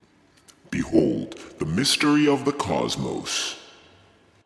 描述：科幻相关的文字由男性说。用AT2020 + USB录制。有效果。